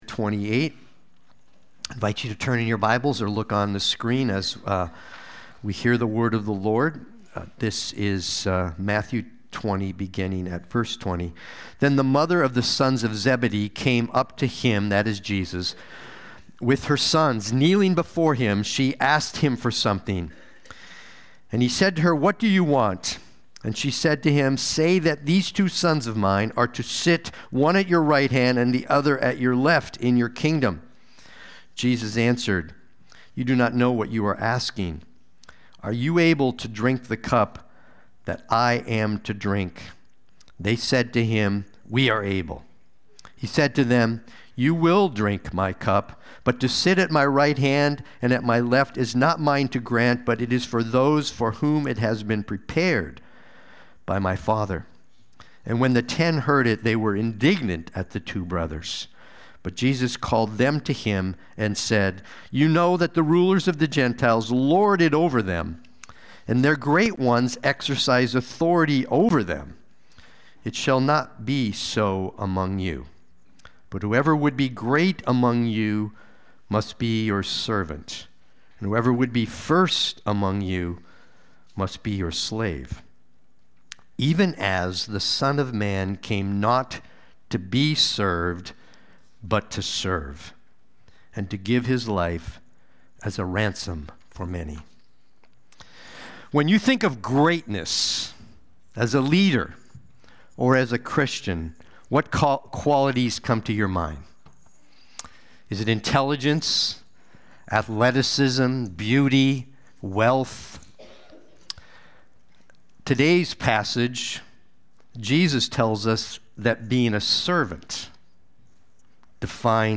Watch the replay or listen to the sermon.
Sunday-Worship-main-12526.mp3